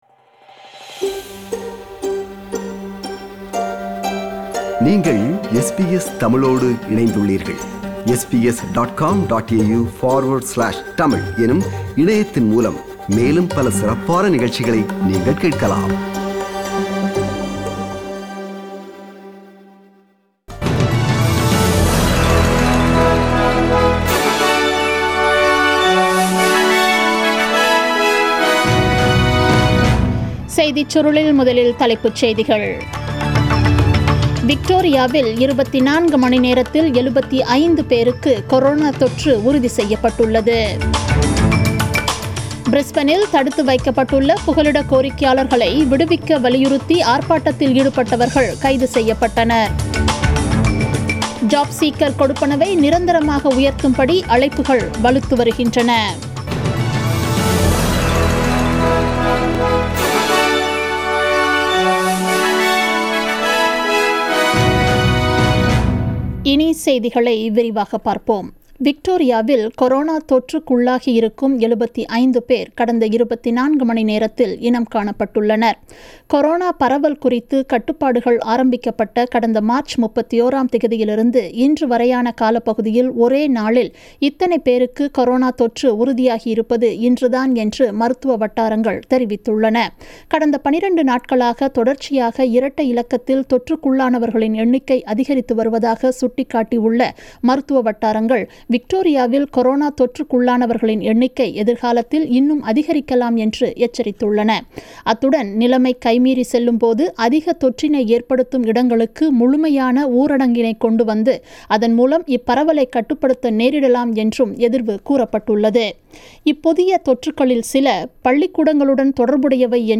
The news bulletin aired on 29 June 2020 at 8pm